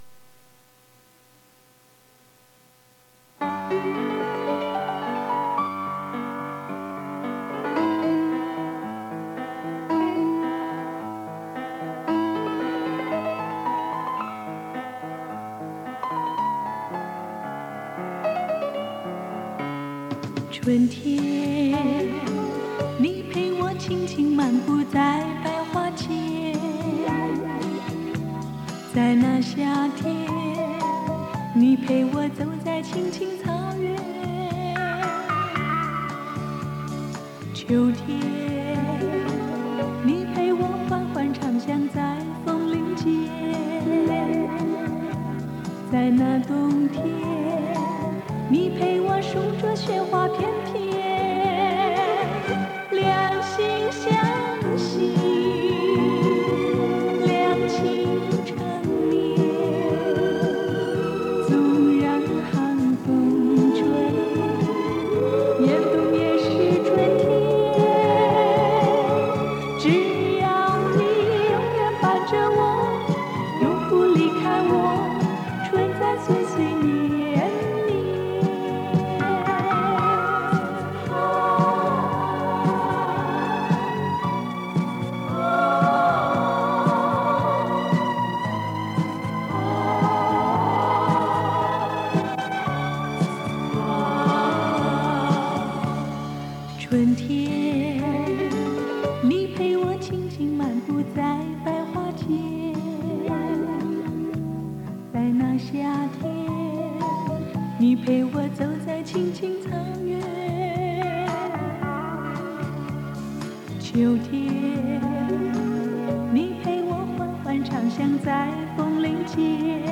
磁带数字化：2022-07-27